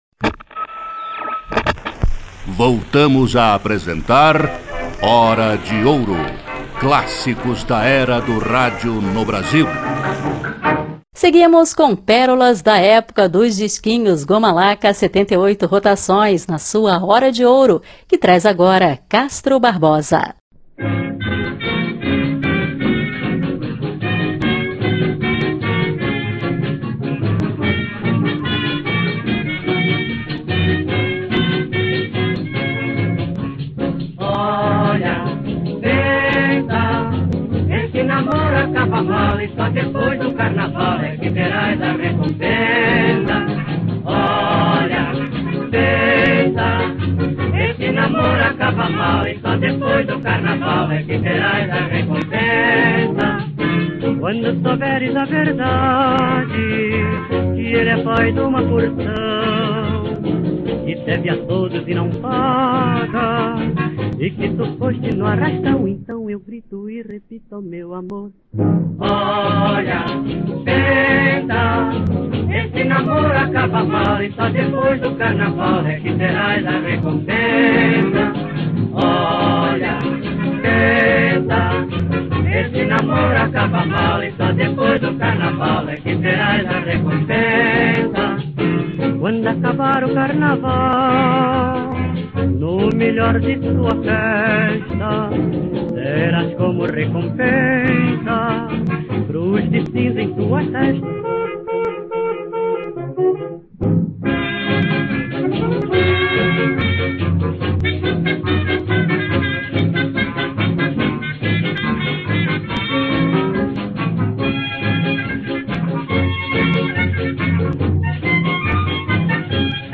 seleção musical do programa mais retrô do seu rádio